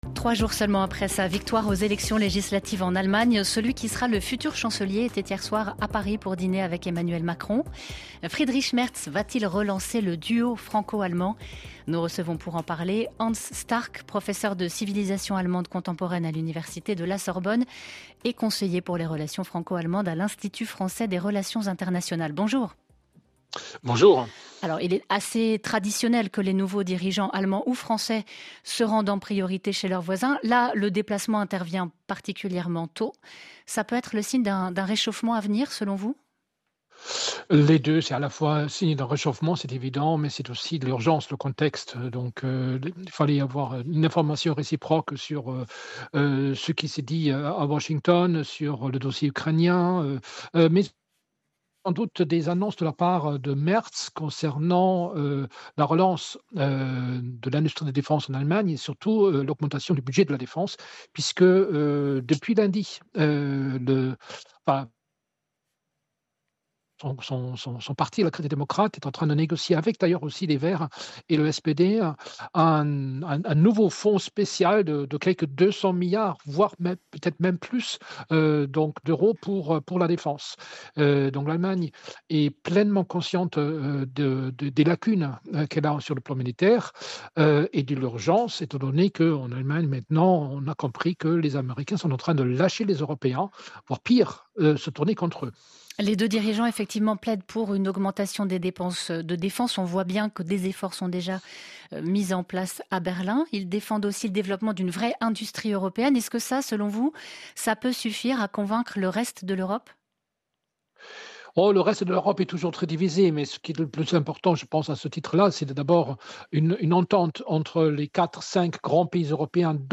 Chaque jour, un invité, spécialiste ou acteur de l’événement, vient commenter l’actualité internationale sur RFI au micro du présentateur de la tranche de la mi-journée.